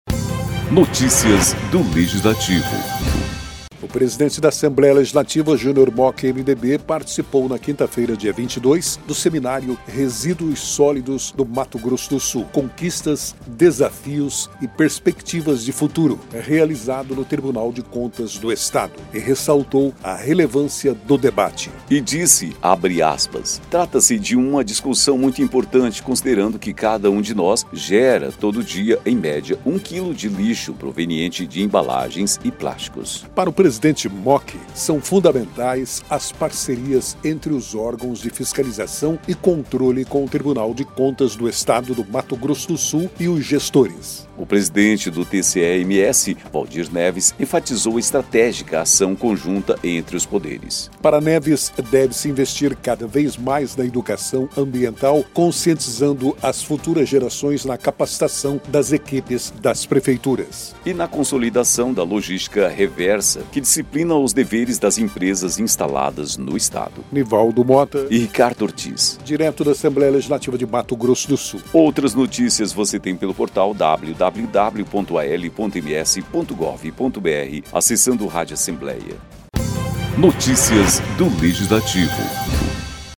O presidente da Assembleia Legislativa, Junior Mochi (MDB), participou nesta quinta-feira (22) do seminário Resíduos Sólidos no MS: Conquistas, Desafios e Perspectivas de Futuro, realizado no Tribunal de Contas do Estado (TCE-MS), e ressaltou a relevância do debate.